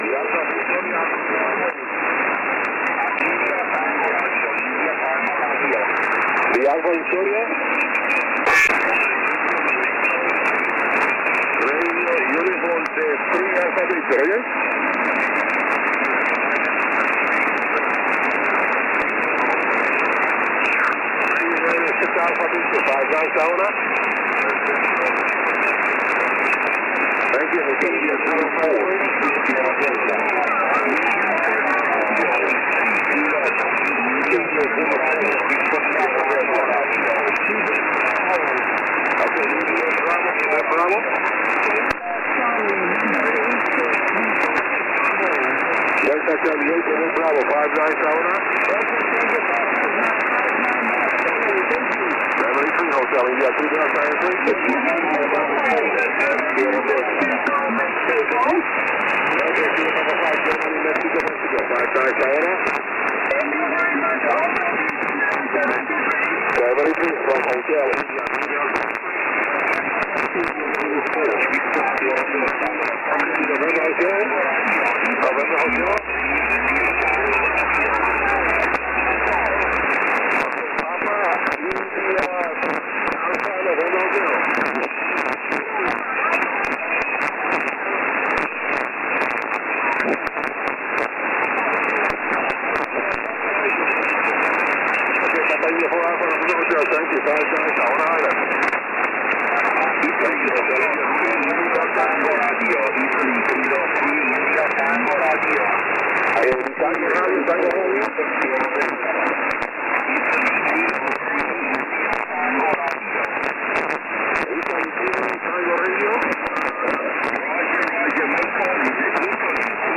10mt SSB in Eu